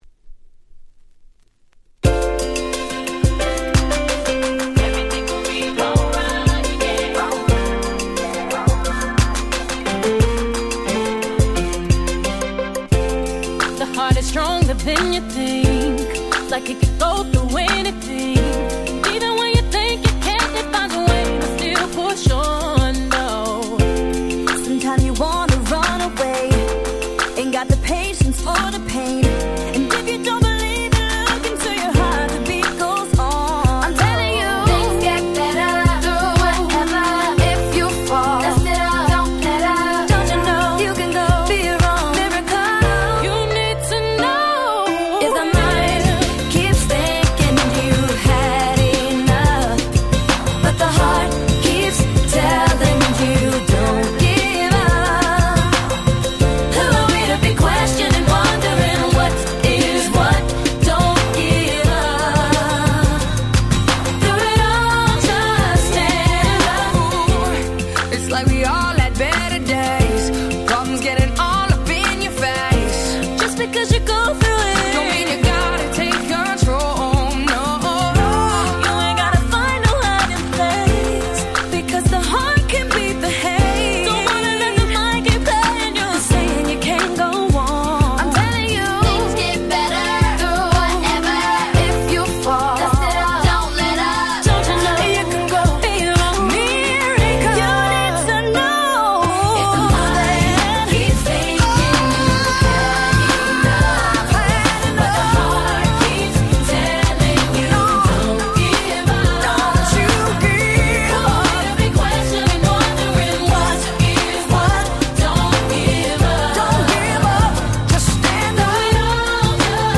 10' Very Nice R&B !!